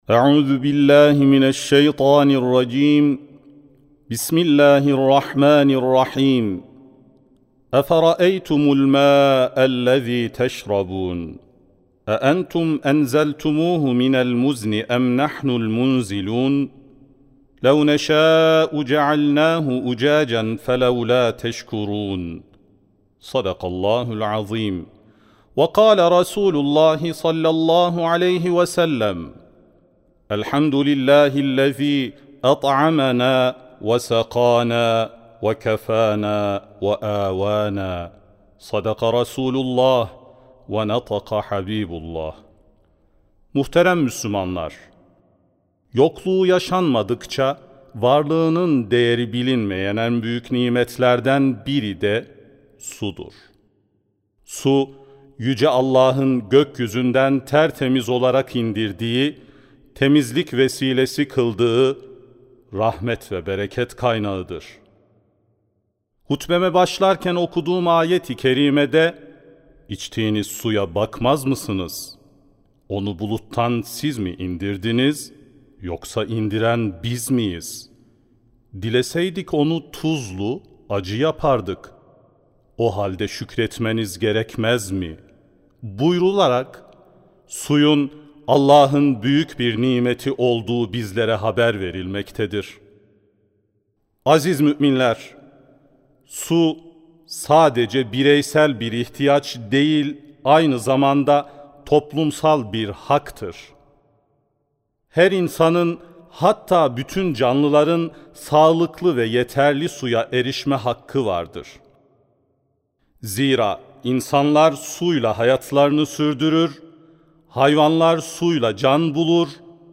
Sesli Hutbe ( Suyumuzu İsraf Etmeyelim ).mp3